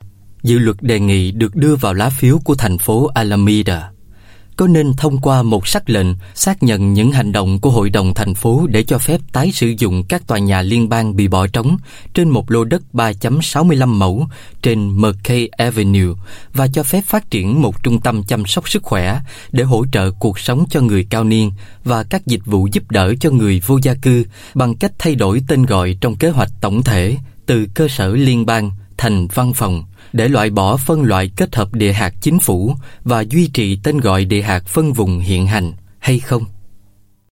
Voice Samples: Voice Sample 03
male